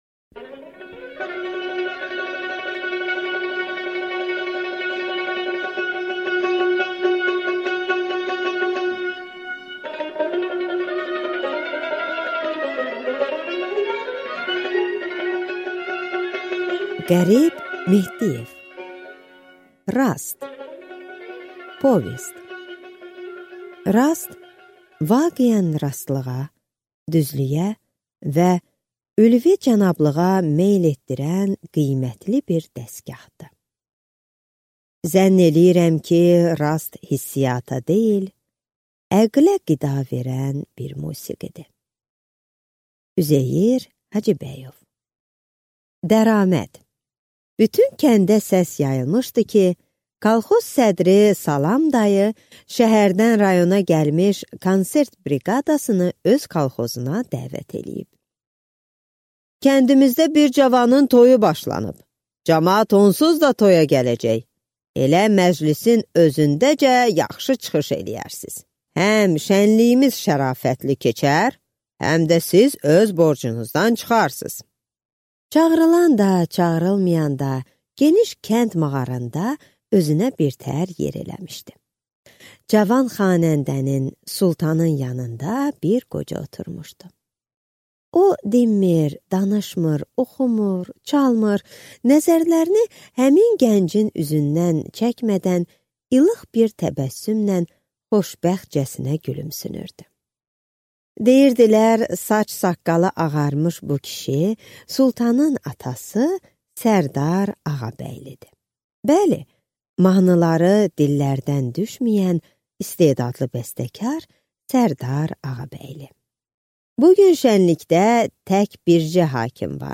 Аудиокнига Rast | Библиотека аудиокниг